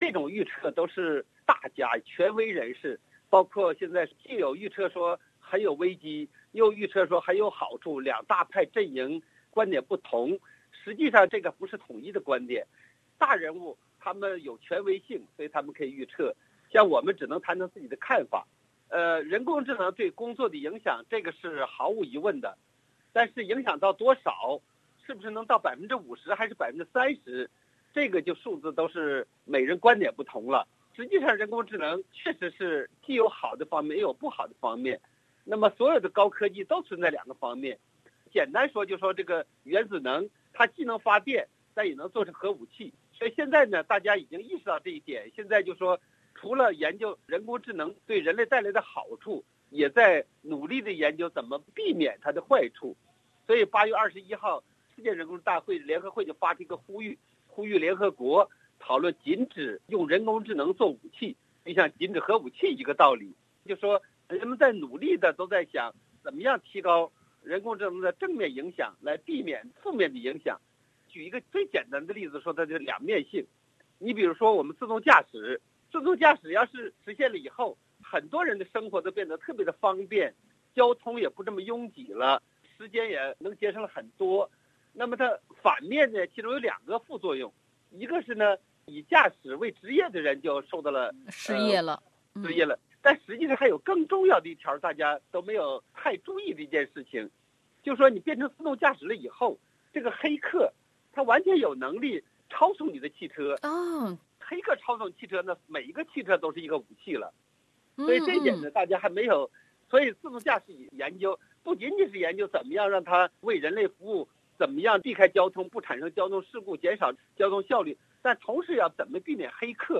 采访中